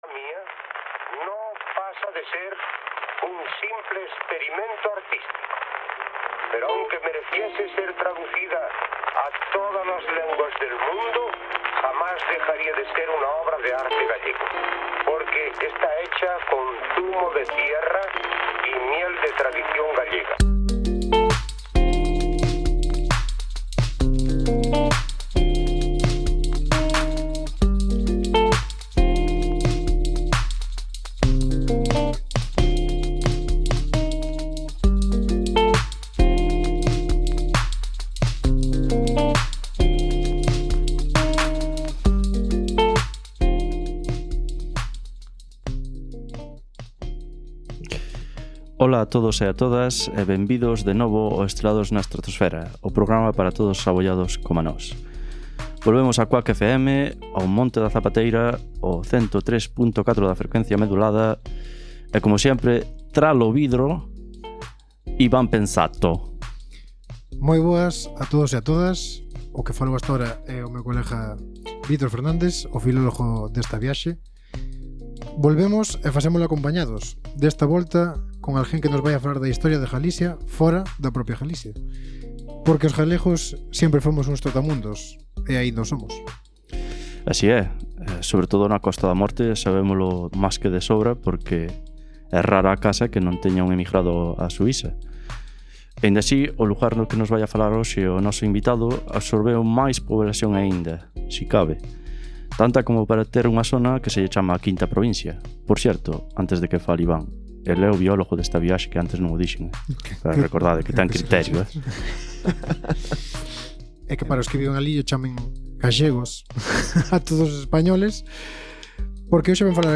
Porque volvemos cunha entrevista, mais desta vez fedellando no pasado e no presente da nosa cultura e historia.
Por iso, neste programa falamos con un descendente deses viaxantes. Un retornado da Arxentina que nos contará a historia do seu avó, que marchou da Galiza ao outro lado do Atlántico, así como a súa propia historia coa mesma viaxe na dirección contraria.
Directo quincenalmente os xoves ás 18:00 en Cuac FM 103.4, e sempre que queiras en RadioCo a app de Cuac FM para Android e iOS.